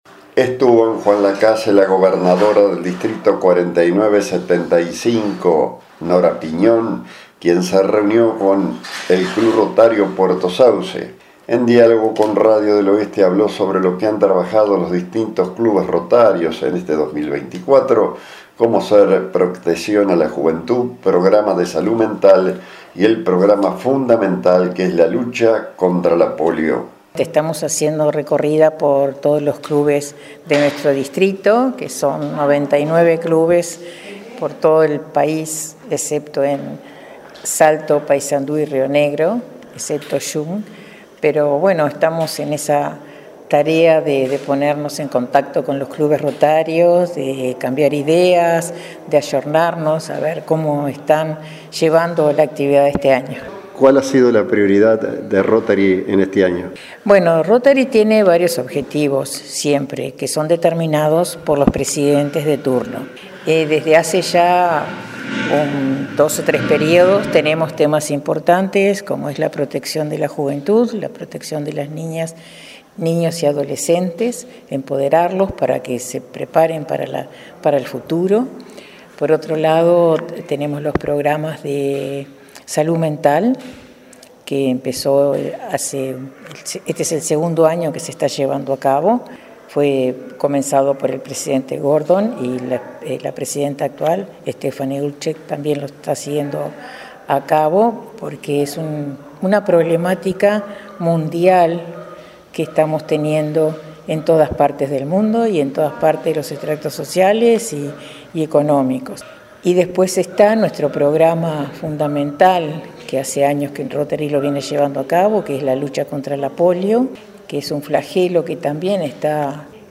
En diálogo con Radio del Oeste se refirió a los principales temas con los cuales se ha trabajado con los distintos clubes en este 2024,como ser protección a la juventud, programa de salud mental y el fundamental que es la lucha contra la polio